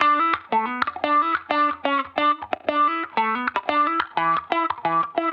Index of /musicradar/sampled-funk-soul-samples/90bpm/Guitar
SSF_StratGuitarProc1_90E.wav